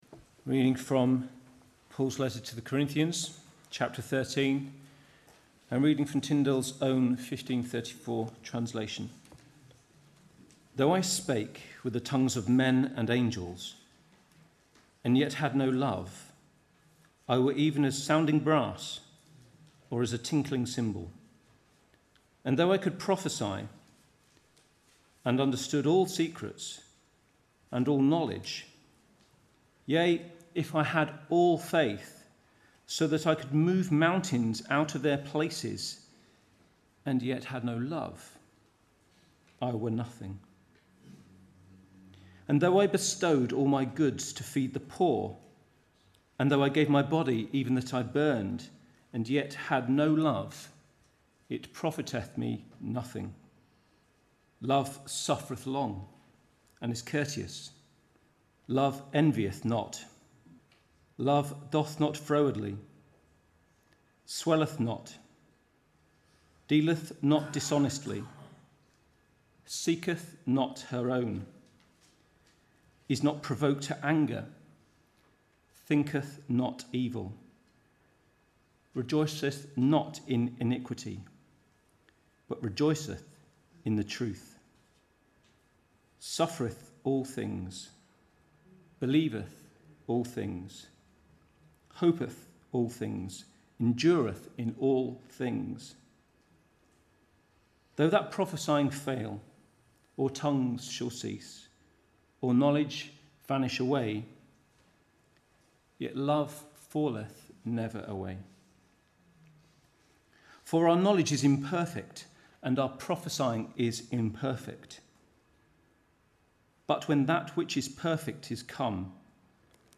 2017 Autumn Lectures